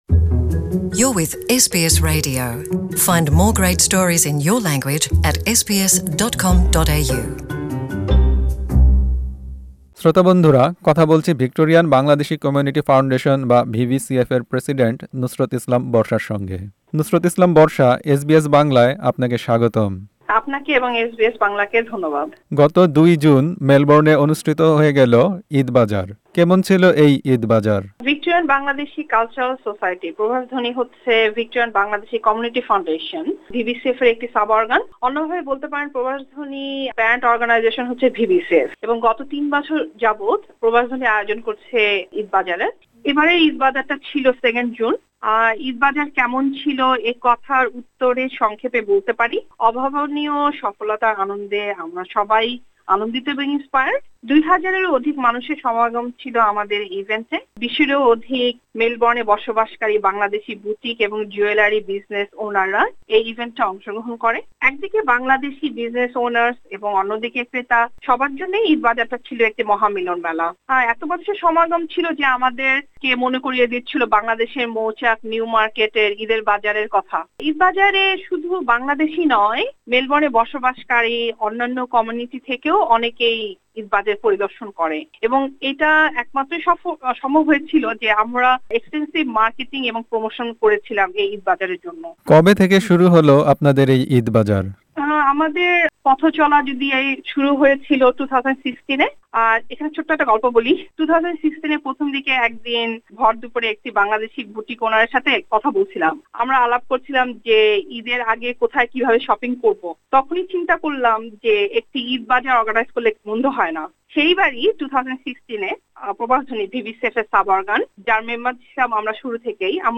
ঈদ বাজার সম্পর্কে এসবিএস বাংলার সঙ্গে কথা বলেছেন